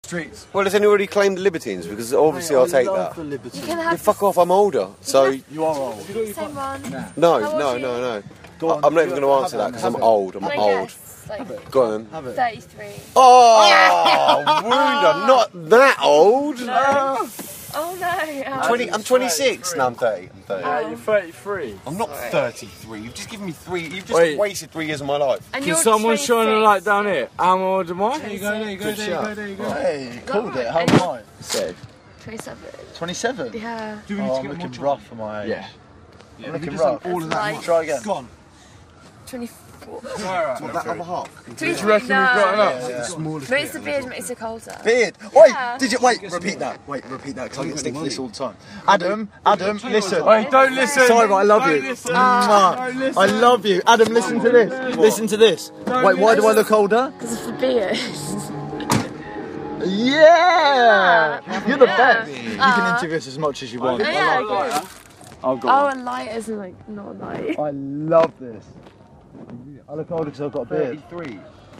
Exclusive Interview with The Scruff – INDIE UNDERGROUND